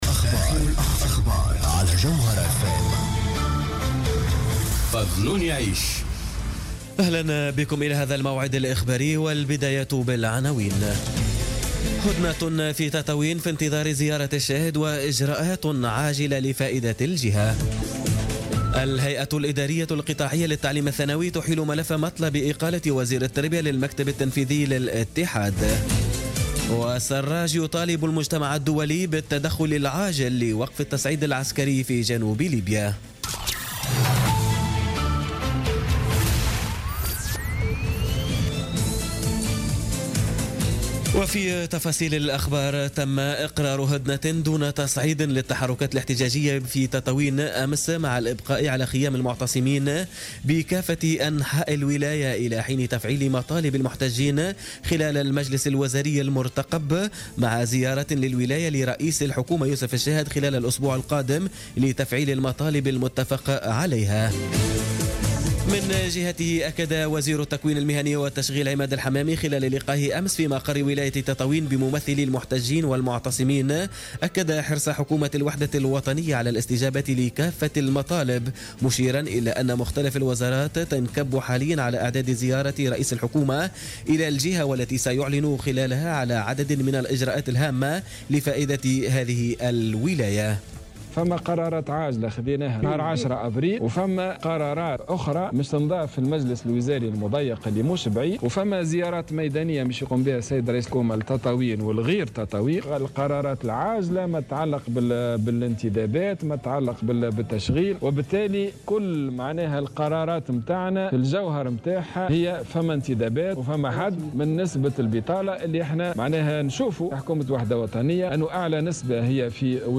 نشرة أخبار منتصف الليل ليوم الأحد 16 أفريل 2017